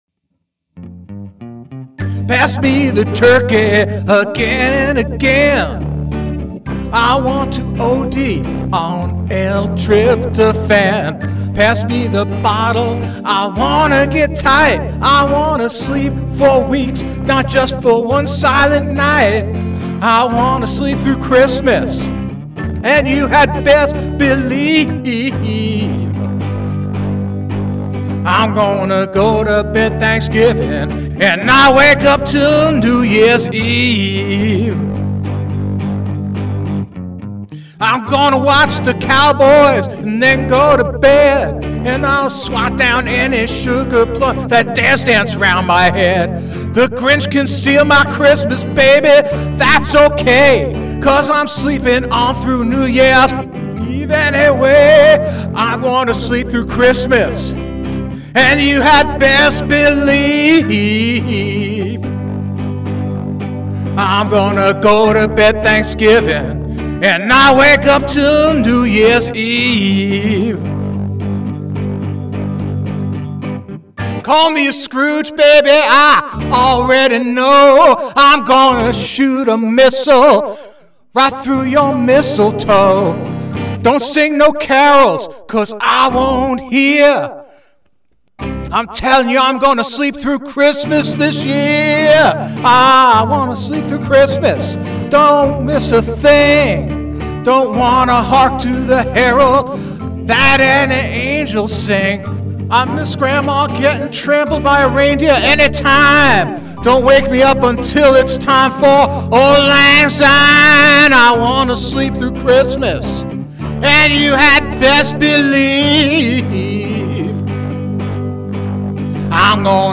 a very rough demo of the soon-to-be holiday classic "